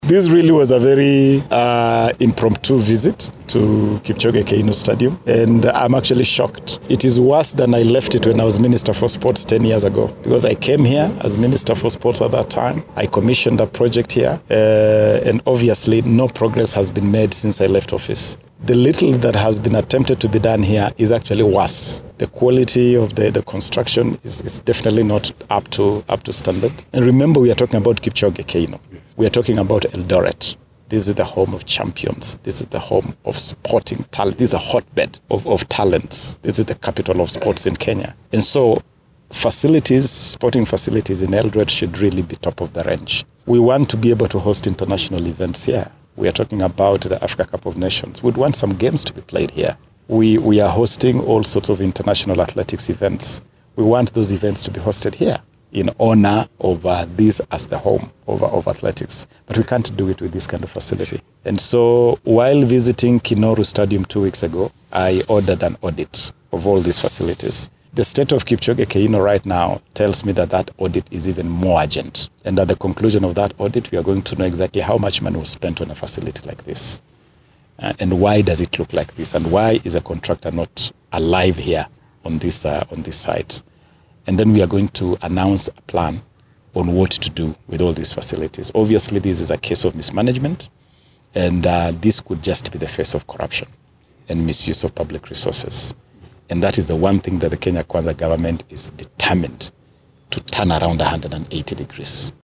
Speaking after the tour, the CS expressed his displeasure over the slow pace of the exercise while putting the contractor on notice for what he termed as failure to act with speed in meeting with the set timelines.
SOUND-BITE-CS-SPORTS-.mp3